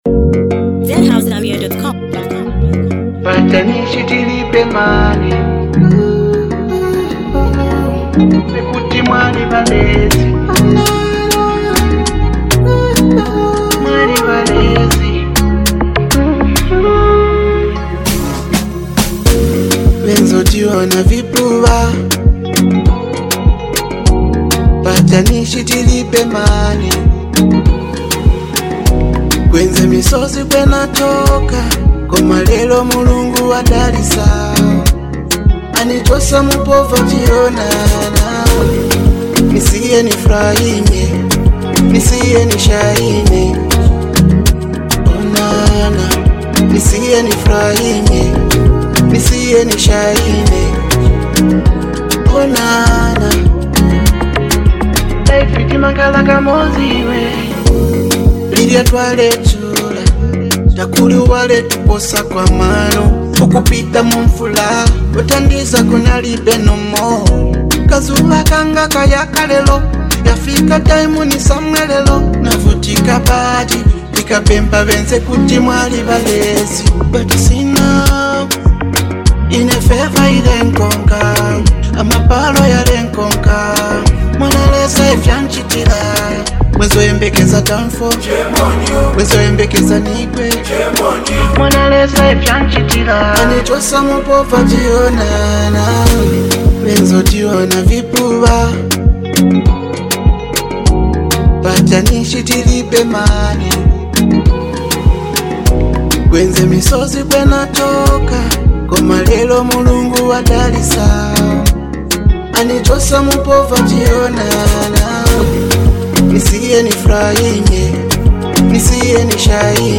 inspiring vibes
A true anthem for the streets!